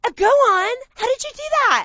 split_how_did_you.wav